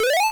The sound the Runner makes in Namco Roulette